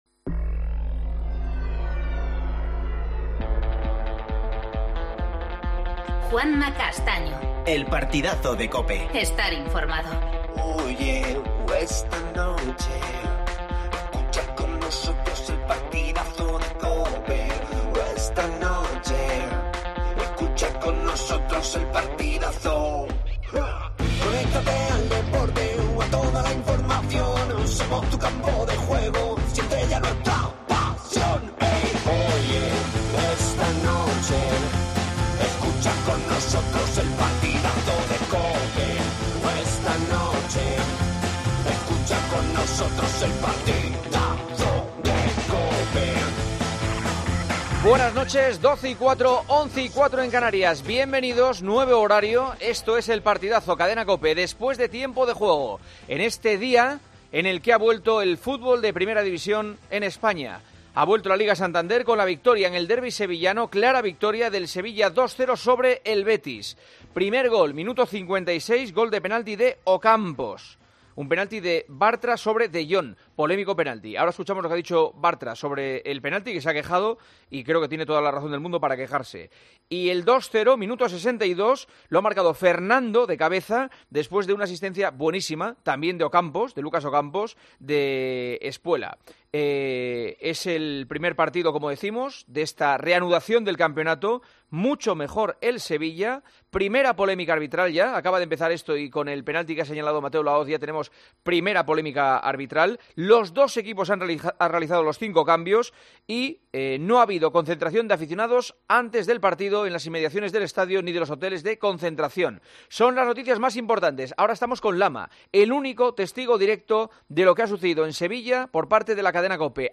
AUDIO: El Sevilla derrota 2-0 al Betis en el primer partido de Primera tras el parón. Entrevistas a Ocampos y Lucas Alcaraz.